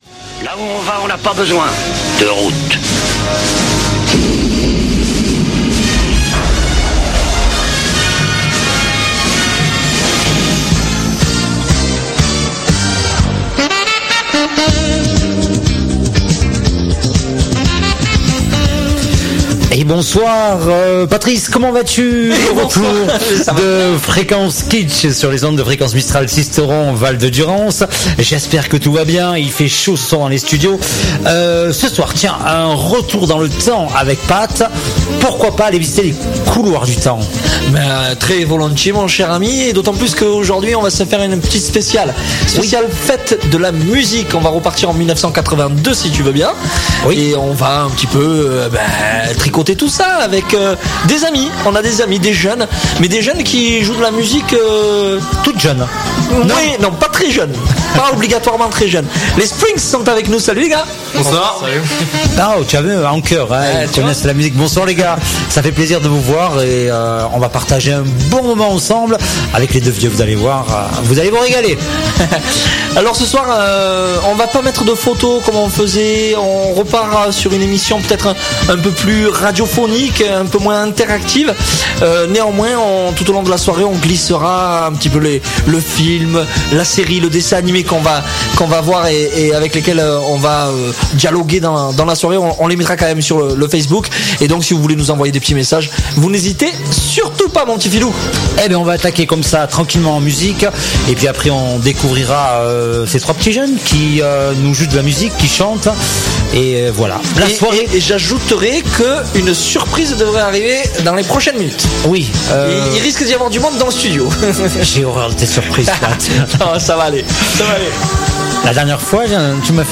L'émission top délire à écouter sur les ondes mistraliennes à partir de 20h30 sur Fréquence Mistral Sisteron 99.2FM, en présence cette fois du groupe de rock sisteronais "The Springs", de talentueux musiciens pour un live mémorable !